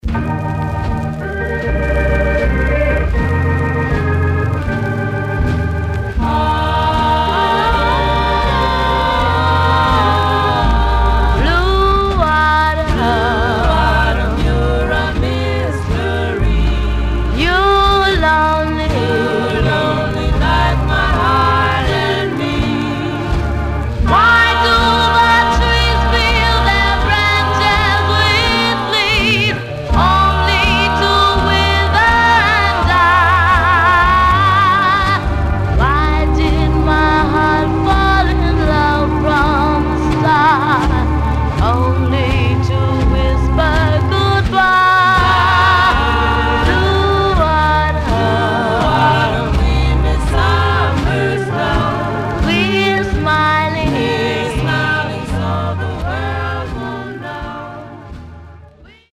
Some surface noise/wear
Mono
Black Female Group